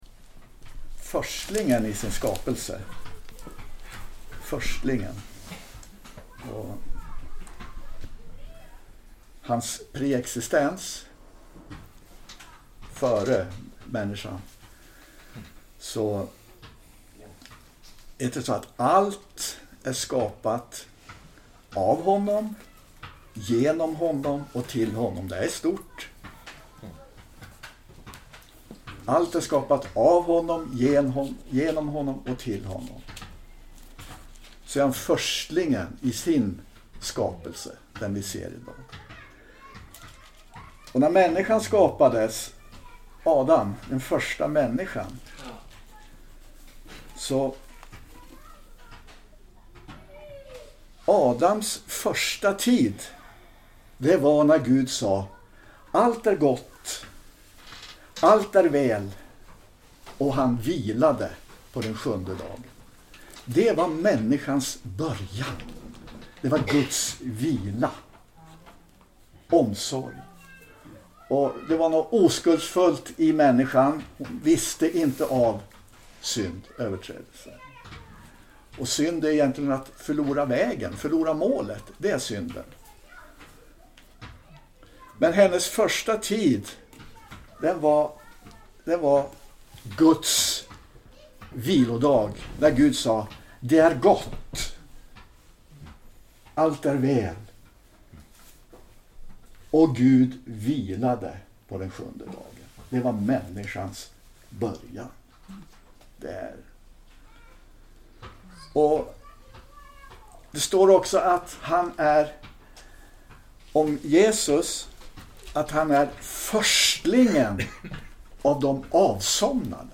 Predikan
inspelad i väckelsemöte i Skälby 31 augusti 2024.